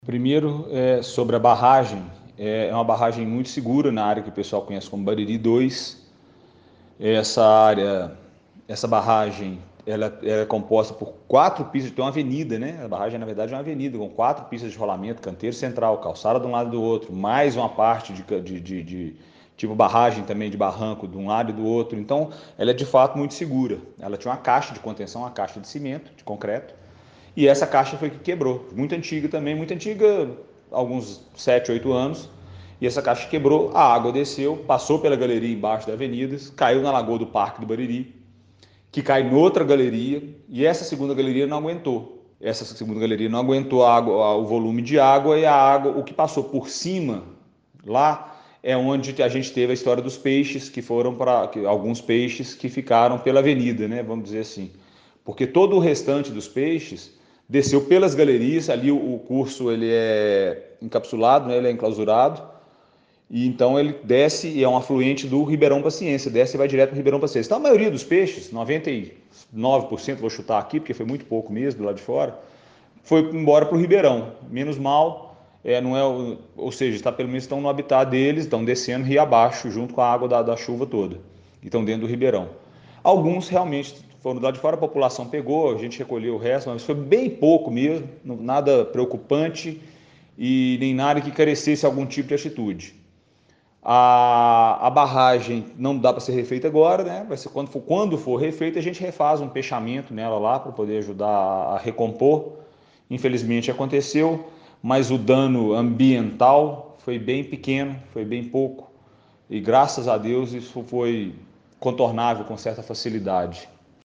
Segundo o secretário José Hermano de Oliveira Franco, o volume de água foi tão grande que levou até alguns peixes para a Avenida Professor Mello Cançado: